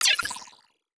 Space UI Touch and Reaction 1.wav